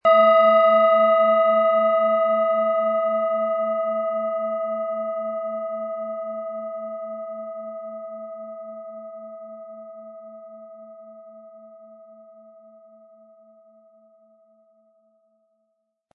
Planetenton 1
PlanetentonBiorythmus Seele
SchalenformBihar
MaterialBronze